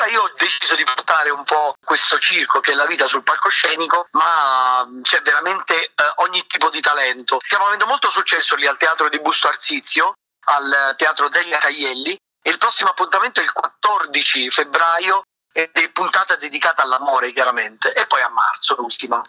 Gabriele-Cirilli-commenta-Il-Cirque-du-Cirill-ai-microfoni-di-Oradio.it_.mp3